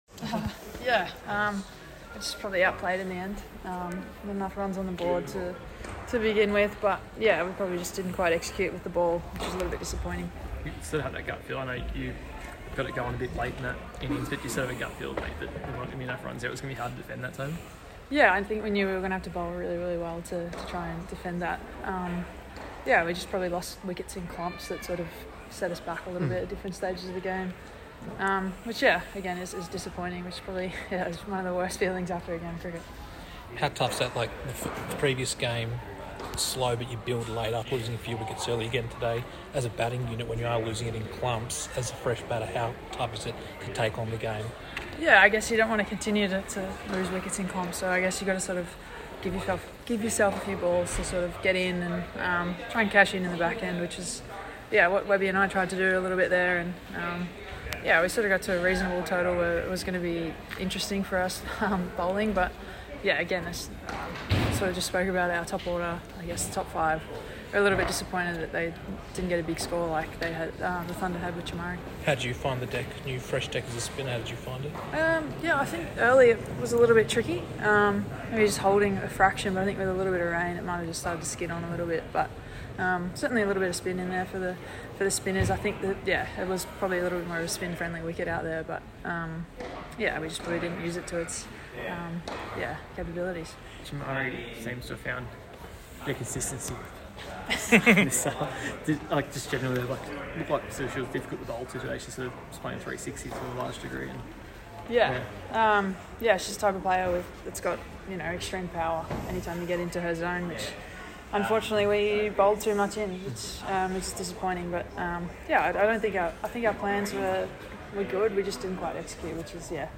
Georgia Wareham (47* and 2 wickets) spoke to media today following the Renegades’ loss to the Thunder at Cricket Central